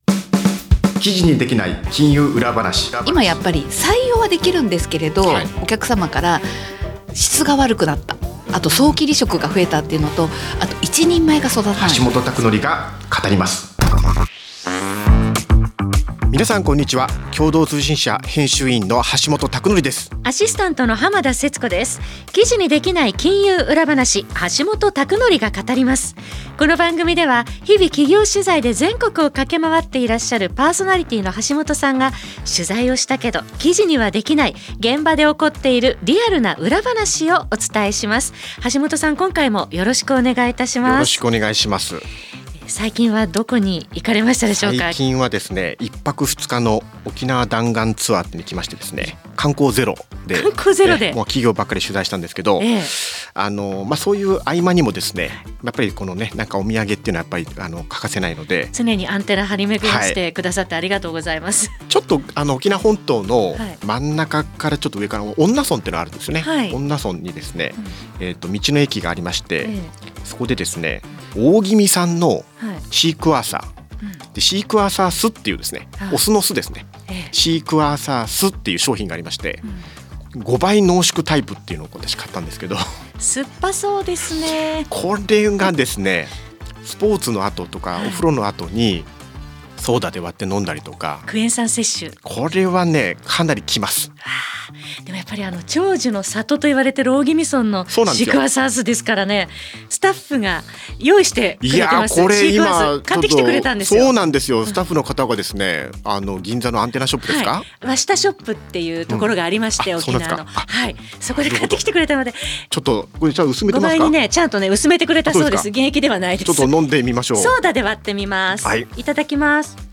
また、金融・行政・地方経済・経営など、ビジネス改革の最前線で活躍するプロフェッショナルをゲストに呼んで、ぶっちゃけトークを展開。